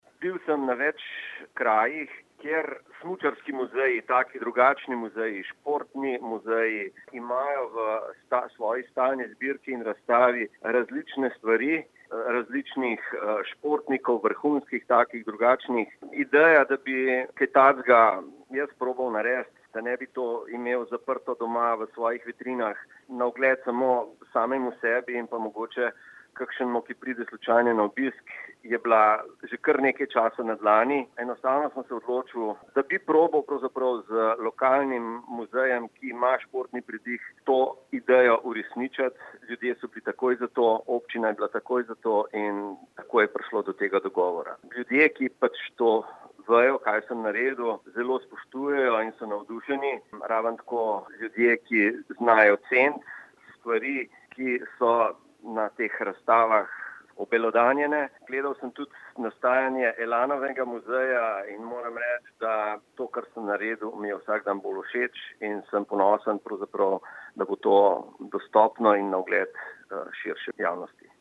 izjava_bojankrizaj.mp3 (1,7MB)